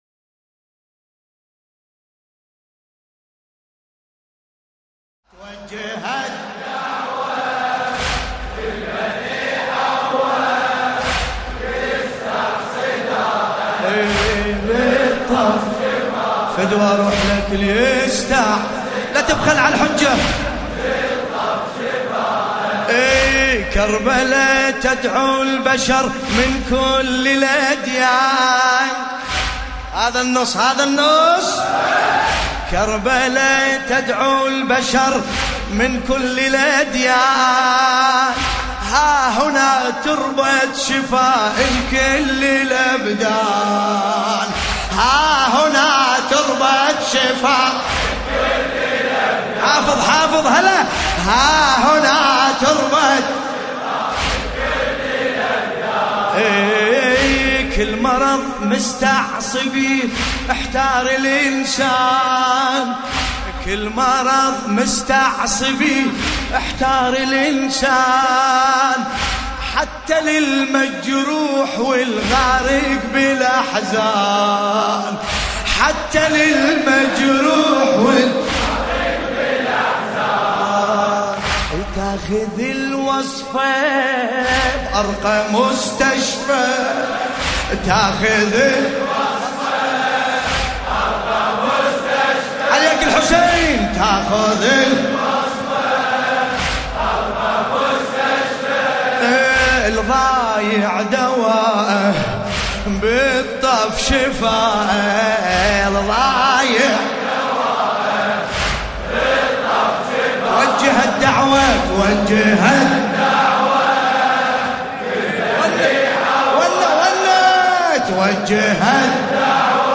في حسينية المرحوم الحاج داود العاشور